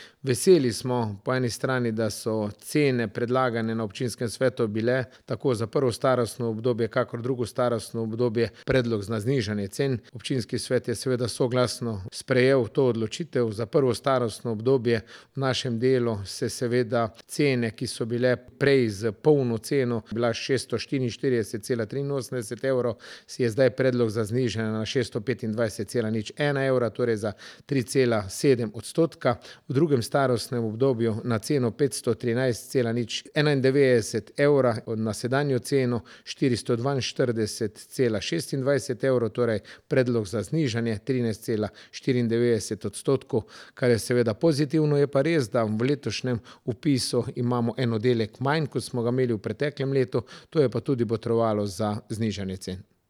Pojasnjuje župan, Franjo Golob :